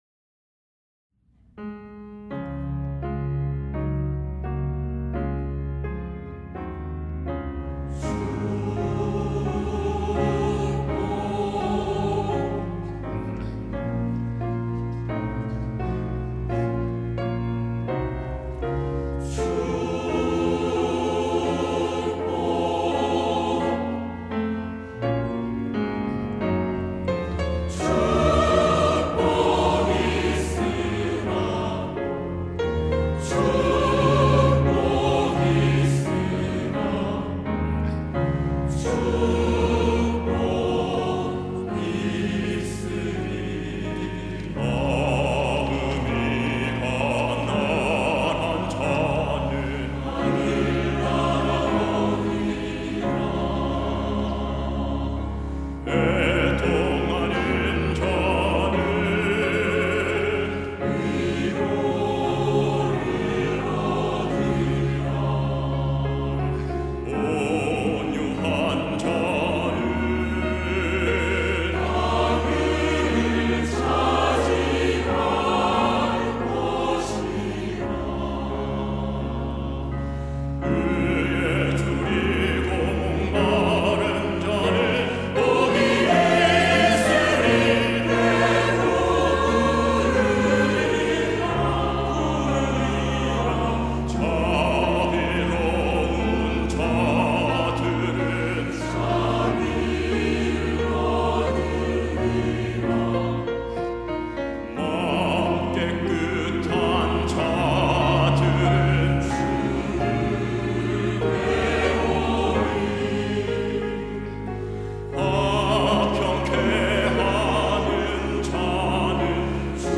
할렐루야(주일2부) - 축복
찬양대